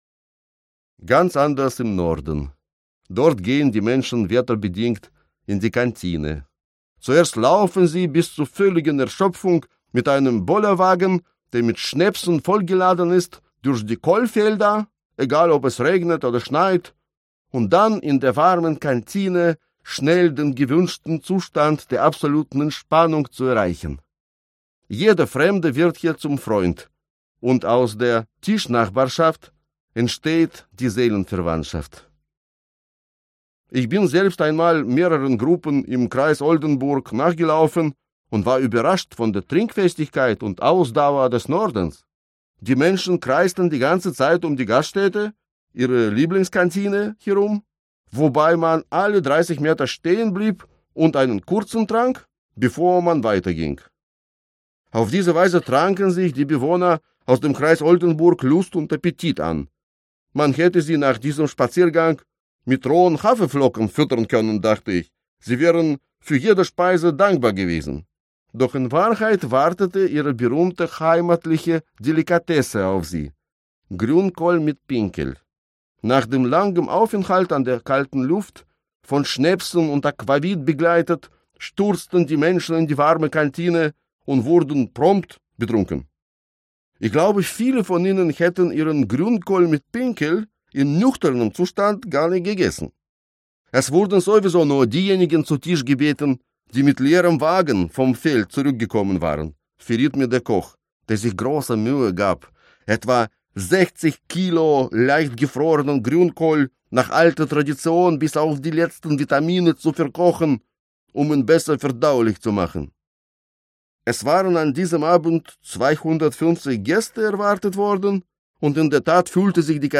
Hörbuch: Mahlzeit!